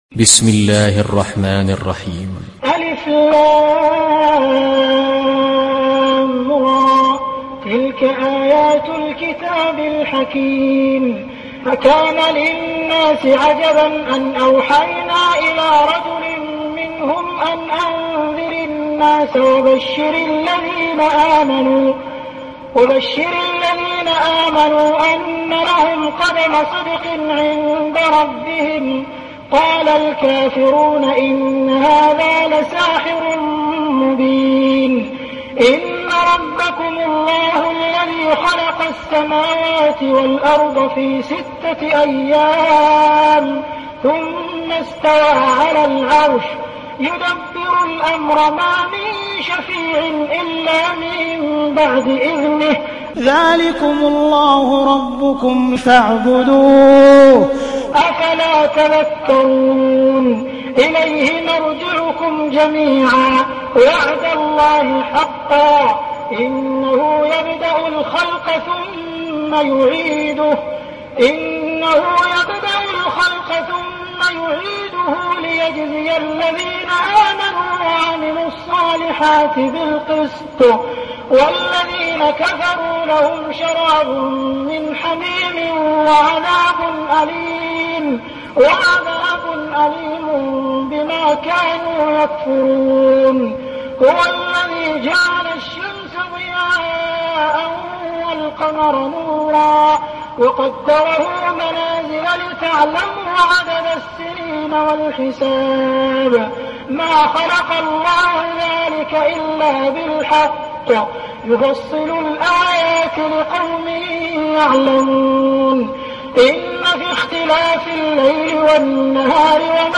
Sourate Yunus Télécharger mp3 Abdul Rahman Al Sudais Riwayat Hafs an Assim, Téléchargez le Coran et écoutez les liens directs complets mp3